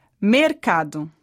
Treine a pronúncia das seguintes palavras.